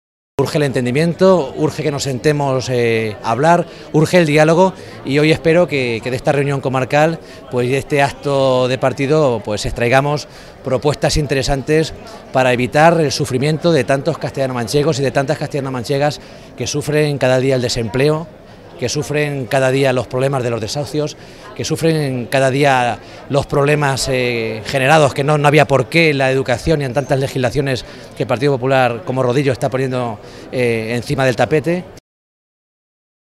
Finalmente, el secretario de la Agrupación local del PSOE y alcalde de La Solana, Luis Díaz-Cacho, también basó su intervención en apelar a acuerdos entre partidos y administraciones, consenso que su juicio urge “para solucionar los problemas y el sufrimiento que tienen los ciudadanos”.